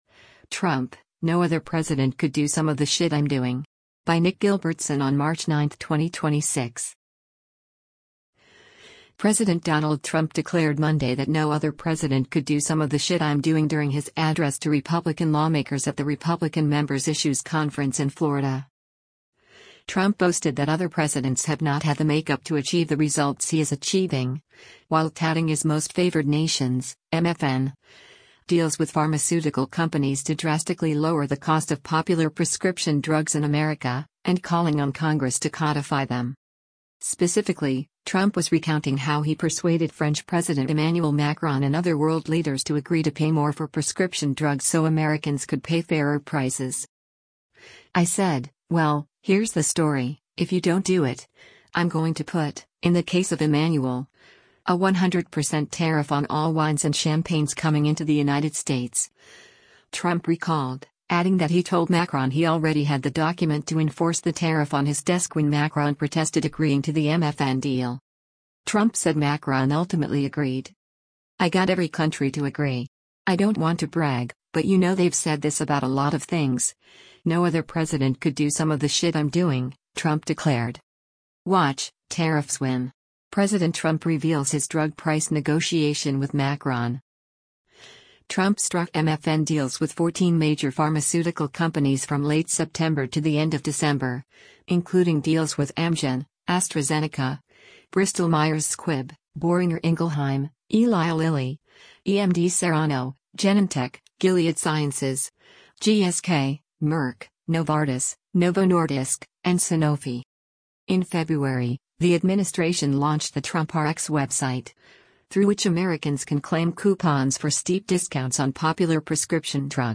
President Donald Trump declared Monday that “no other president could do some of the shit I’m doing” during his address to Republican lawmakers at the Republican Members Issues Conference in Florida.